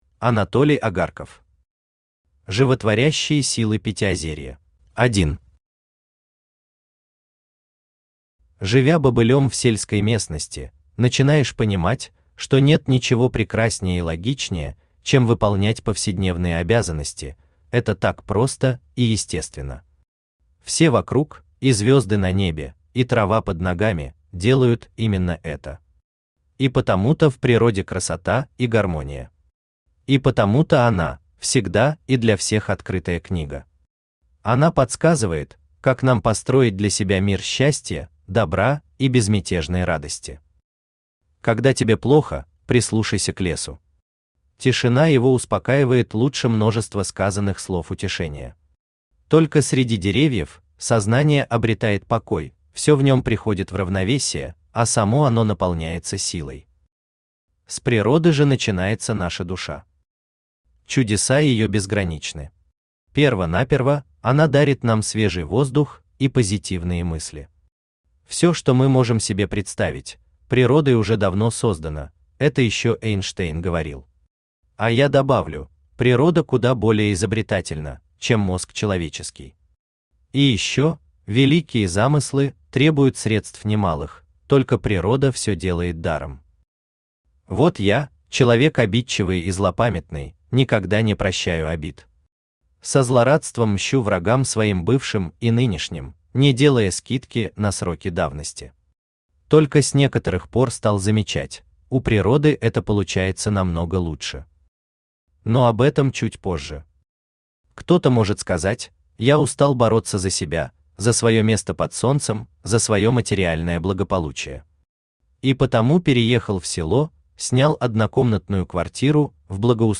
Aудиокнига Животворящие силы Пятиозерья Автор Анатолий Агарков Читает аудиокнигу Авточтец ЛитРес.